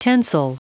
Prononciation du mot tensile en anglais (fichier audio)
Prononciation du mot : tensile